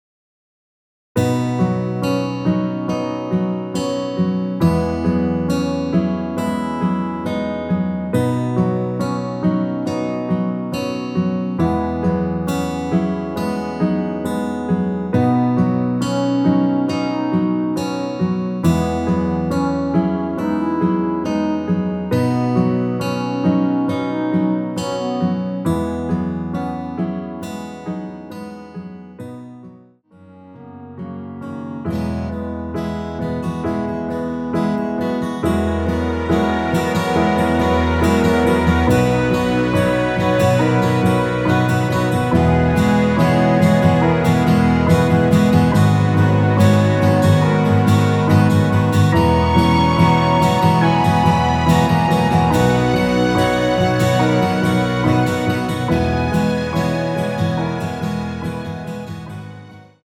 원키에서(-1)내린 멜로디 포함된 MR입니다.
멜로디 MR이란
앞부분30초, 뒷부분30초씩 편집해서 올려 드리고 있습니다.
중간에 음이 끈어지고 다시 나오는 이유는